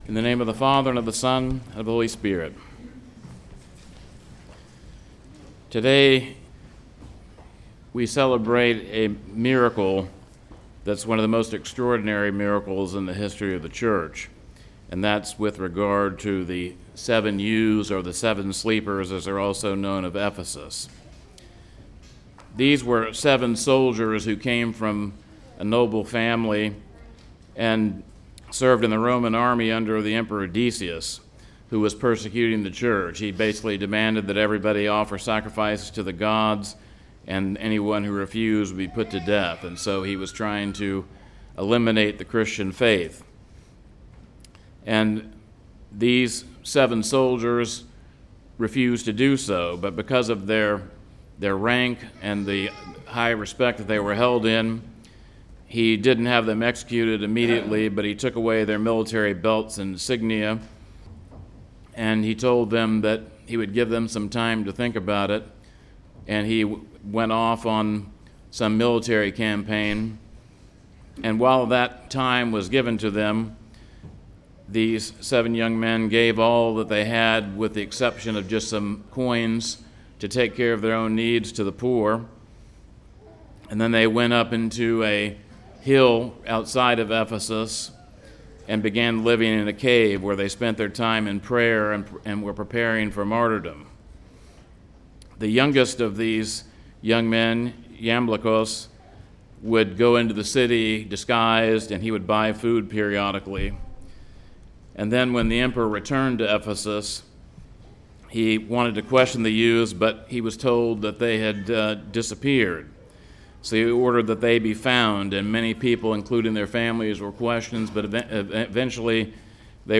2025 The Seven Sleepers of Ephesus Preacher